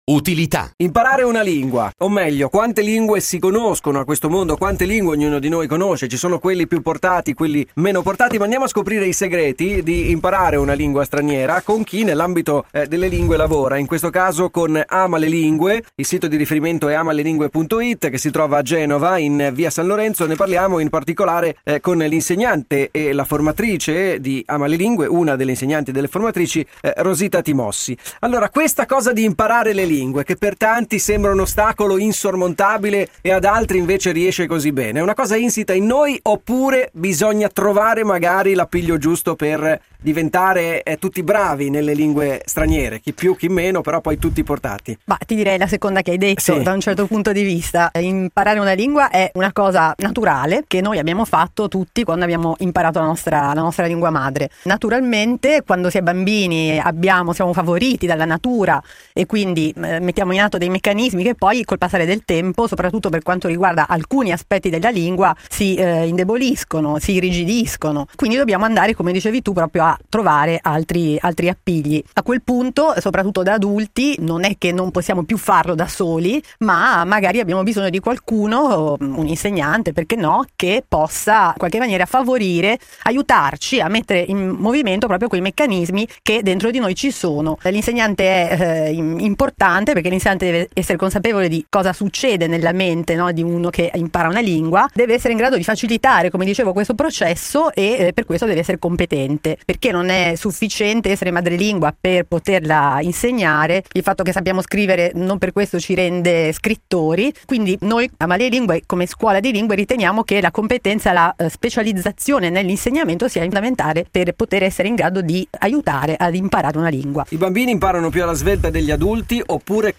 Ecco qui l’intervista di Radio Babboleo ad Amalelingue.